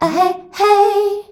AHEHEY  E.wav